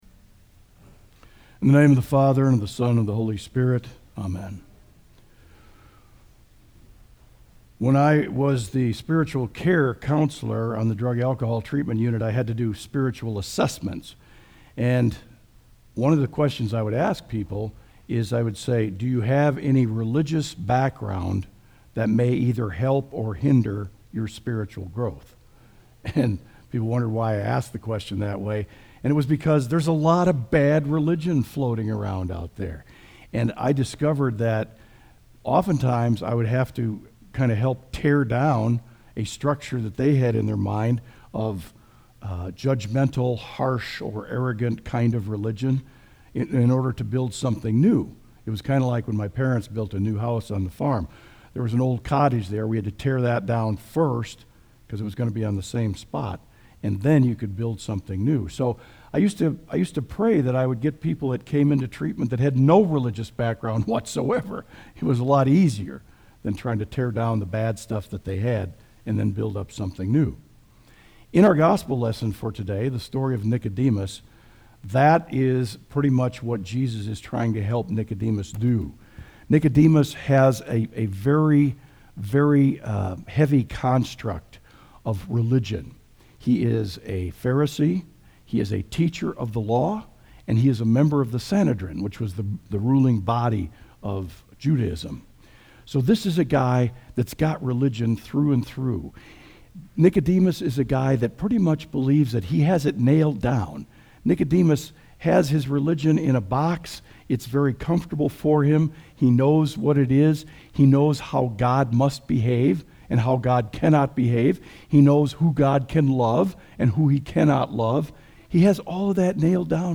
Audio Sermon “Good Religion”